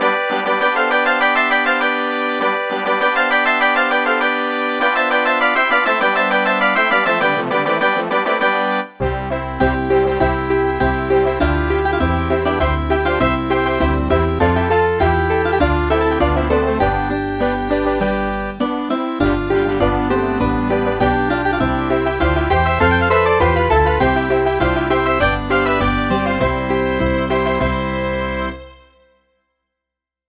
Converted from .mid to .ogg